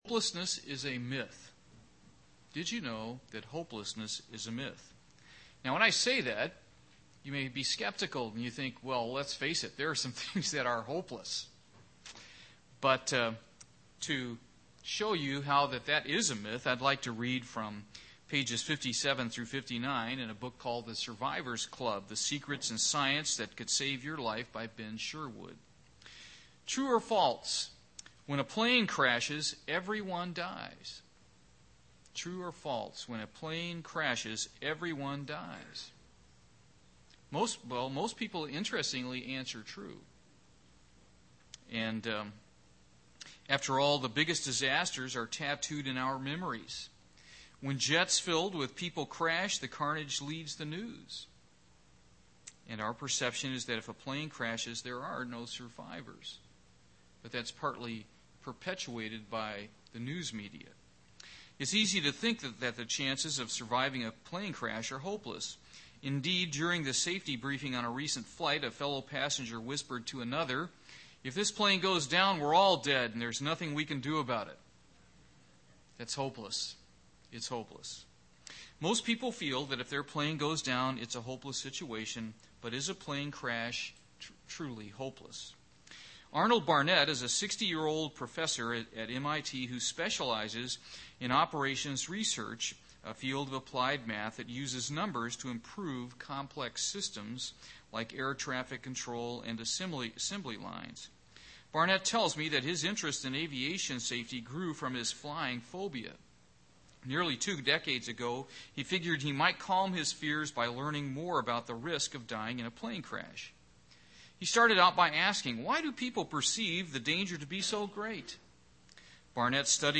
Given in Portsmouth, OH Paintsville, KY
UCG Sermon Studying the bible?